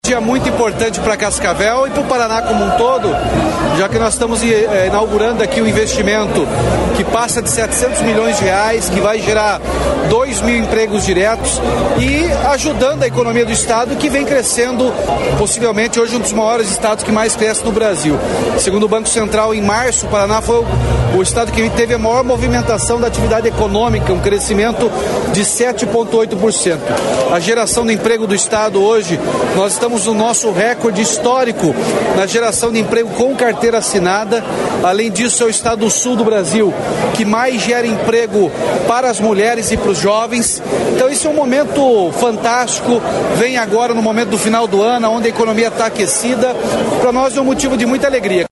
Player Ouça GOVERNADOR RATINHO JÚNIOR